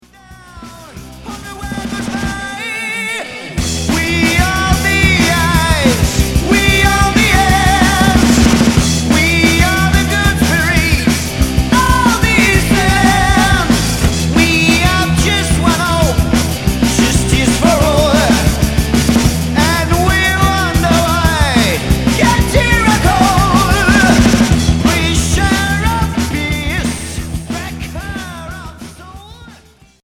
Heavy métal Unique 45t retour à l'accueil